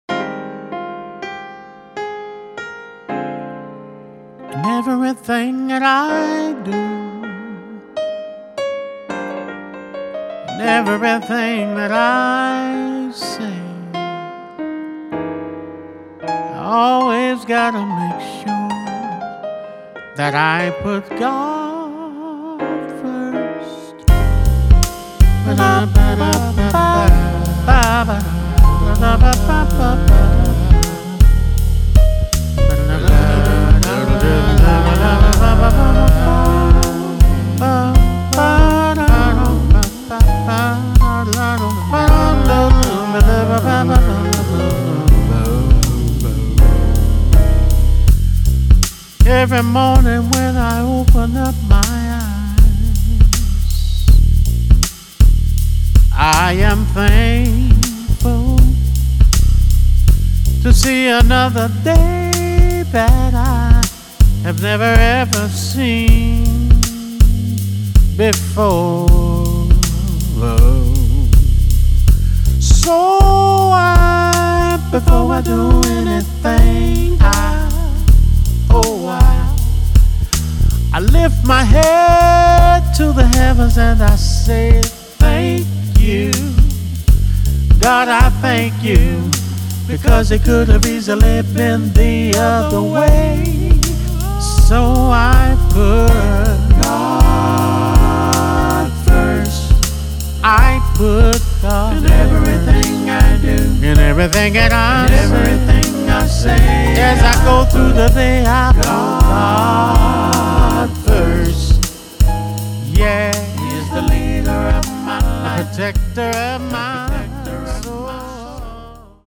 JAZZ GOSPEL